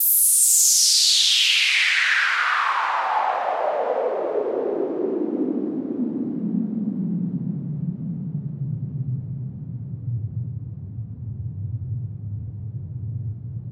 White Noise.wav